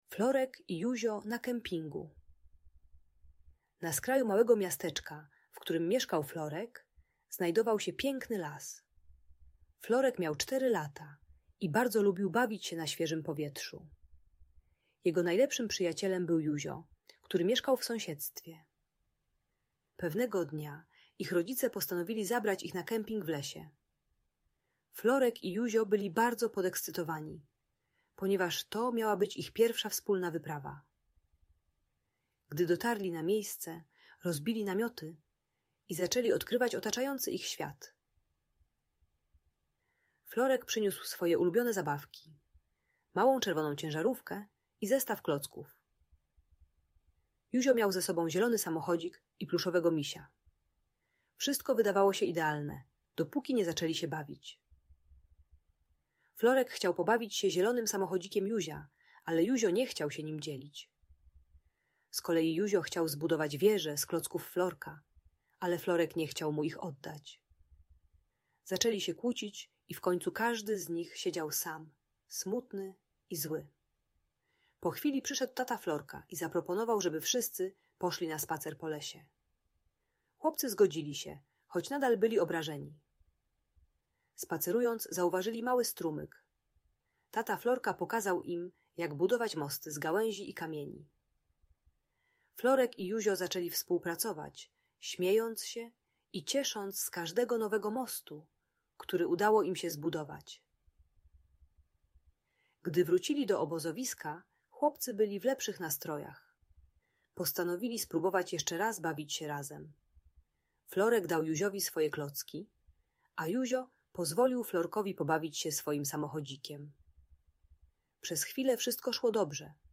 Historia Florka i Józia na Kempingu - Audiobajka